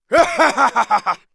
cheers2.wav